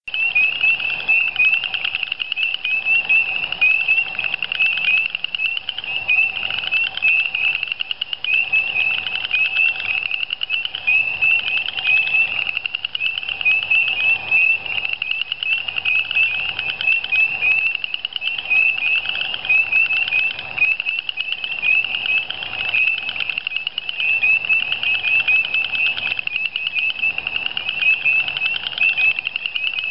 This is a nice Winter chorus of Hyla crucifer bartramiana, and Pseudacris nigrita.
Peepers.mp3